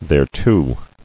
[there·to]